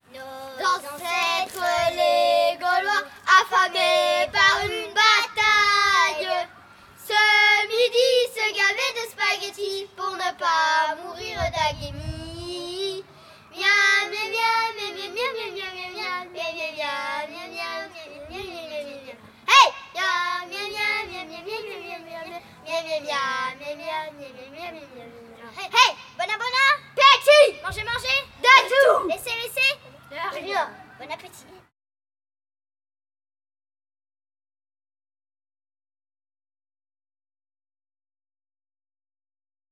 Genre : chant
Type : chant de mouvement de jeunesse
Interprète(s) : Les Scouts de Gilly
Lieu d'enregistrement : Gilly
Chanté avant de manger.